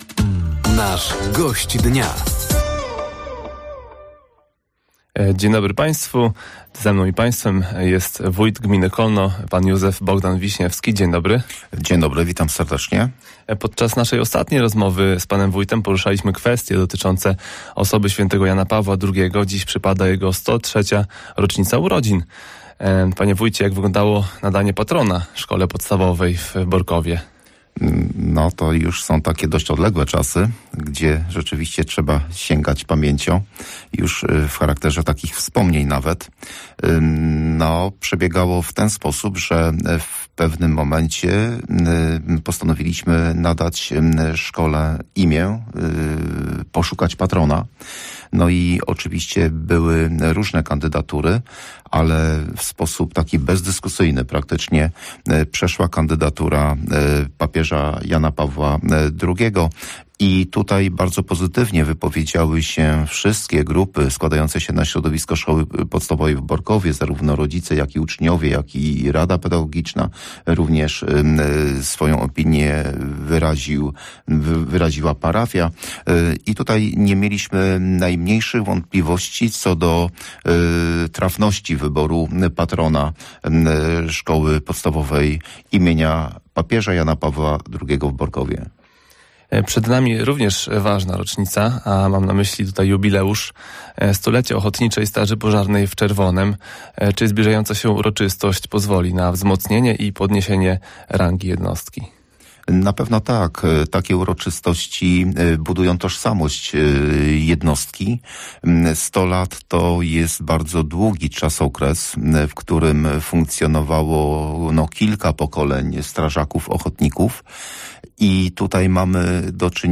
Wójt gminy Kolno, Józef Bogdan Wiśniewski był Gościem Dnia Radia Nadzieja. Na początku rozmowy wójt przypomniał o tym, jak święty Jan Paweł II został patronem szkoły podstawowej w Borkowie. Rozmowa dotyczyła również Ochotniczej Straży Pożarnej, ścieżek rowerowych, termomodernizacji szkół i wymiany oświetlenia w gminie.